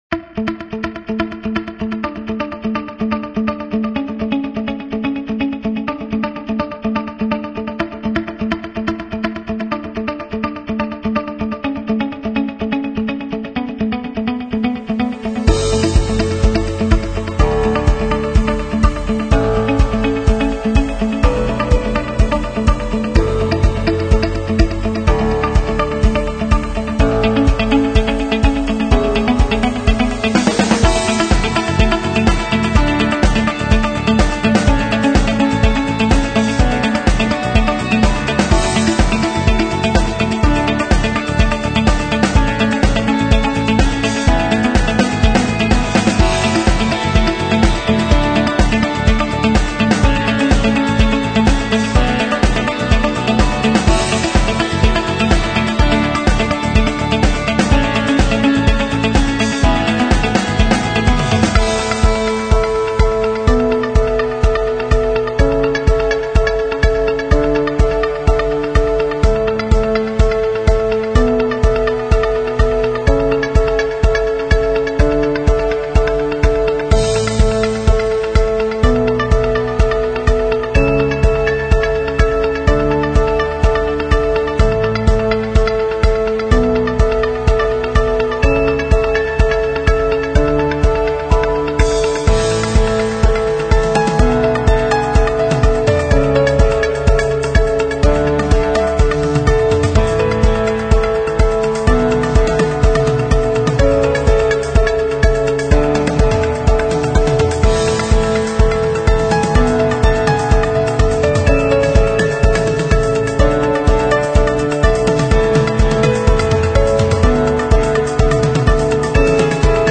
描述：这种企业音乐有一种令人振奋和积极的感觉。 它是用现代流行摇滚风格写成的，带有希望、激励和乐观的情绪。